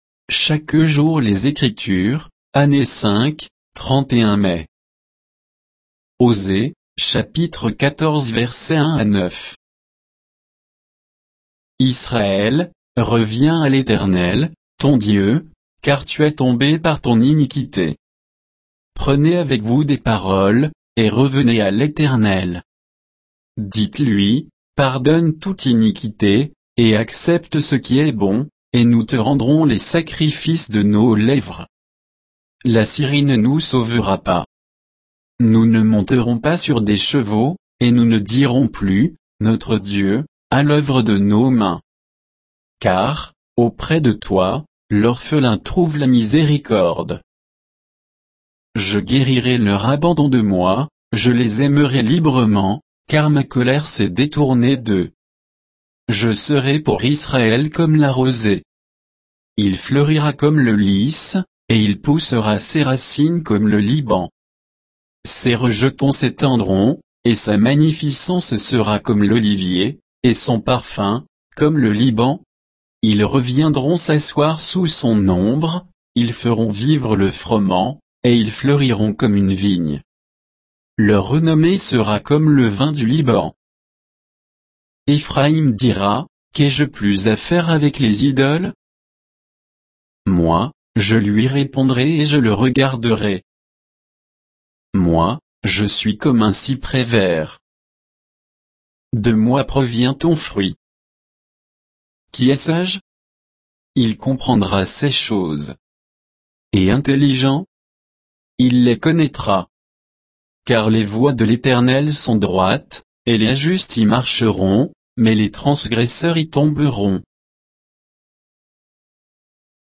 Méditation quoditienne de Chaque jour les Écritures sur Osée 14, 1 à 9